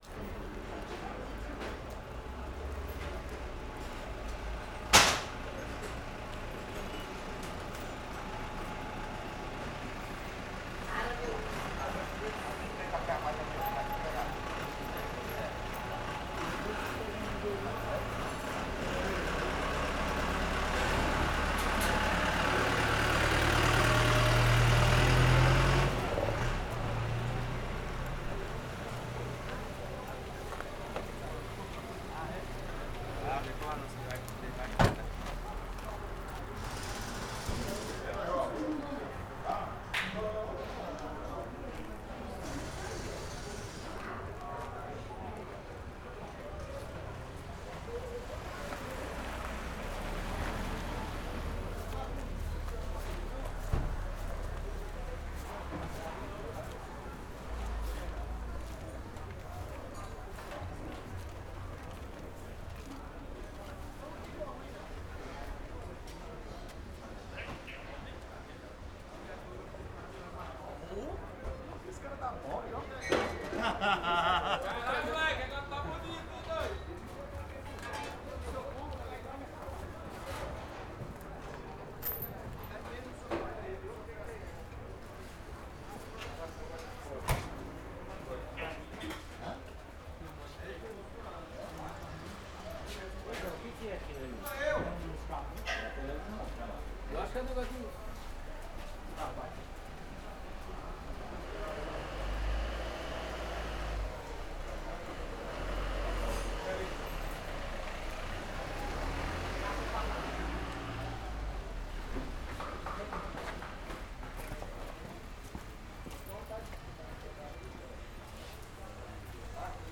Oficina carro liga e sai vozes masculinas Carro , Carro liga e sai , Oficina de carro , Vozes masculinas Brasília , Ceilândia Stereo
CSC-04-168-OL- Oficina carro liga e sai vozes masculinas.wav